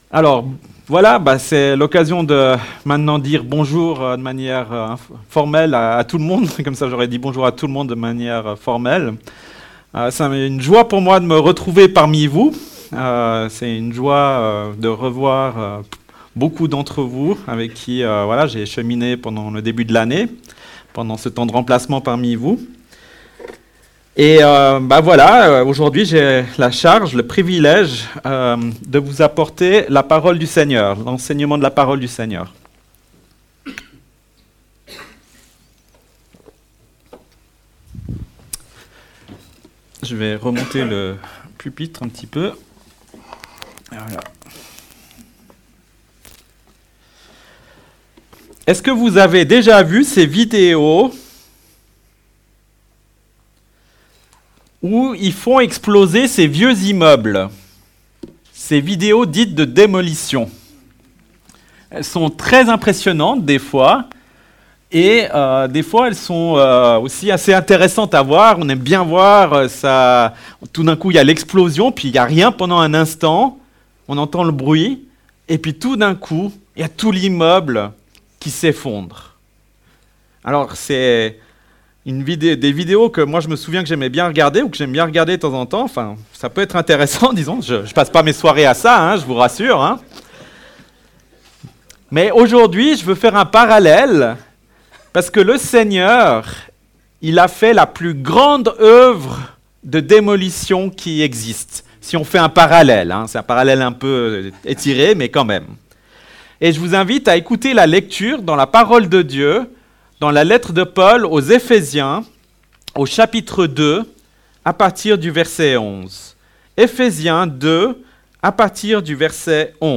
La lettre de Paul aux Ephésiens aborde les thèmes de l’unité des croyants, ainsi que comment Dieu nous a réconcilié avec Lui-même par la mort de Jésus à la croix. Dans cette prédication à écouter, découvrons :